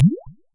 Bullet_Water_Splash.wav